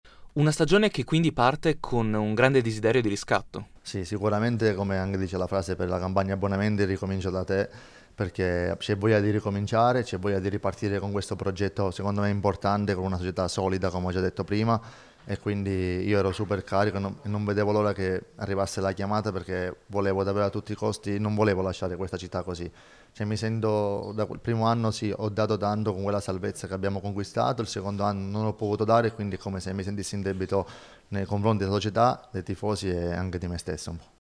Ecco le dichiarazioni raccolte il giorno della presentazione: